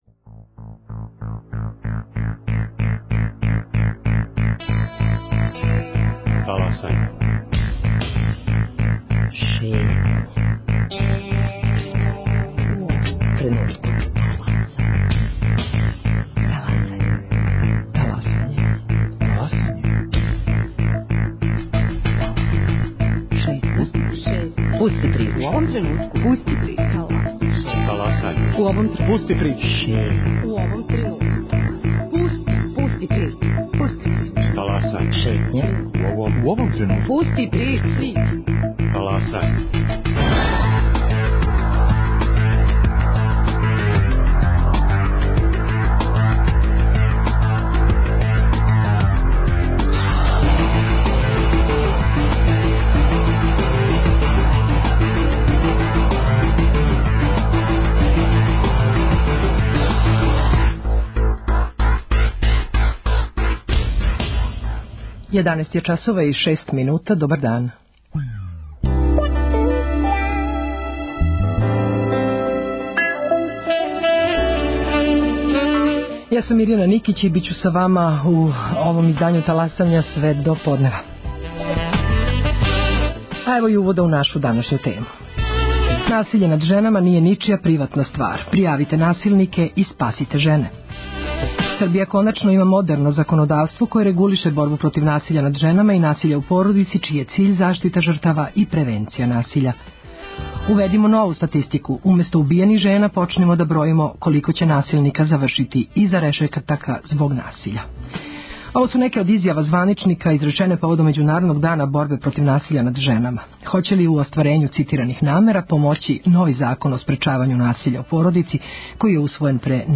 Да ли ће, у остварењу цитираних намера, помоћи нови Закон о спречавању насиља над женама, који је усвојен пре неколико дана? Гошће су: судија Уставног суда и професор доктор Марија Драшкић и потпредседница Народне скупштине Гордана Чомић.